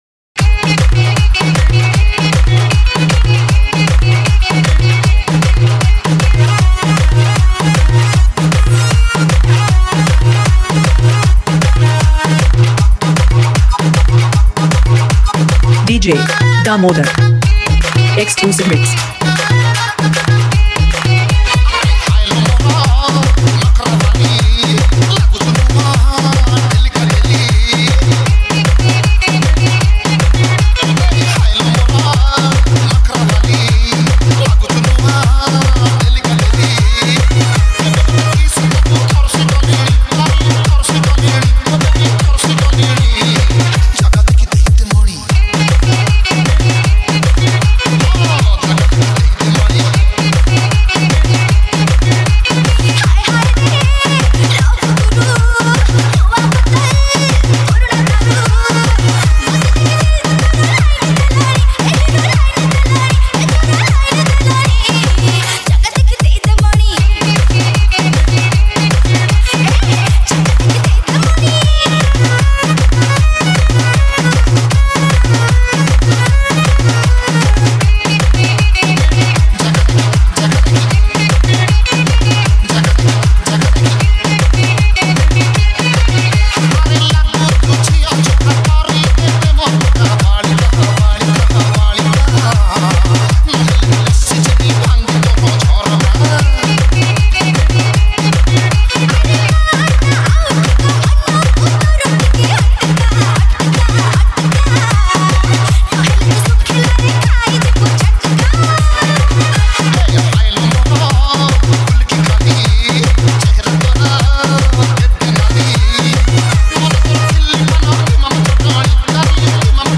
Odia Dj Song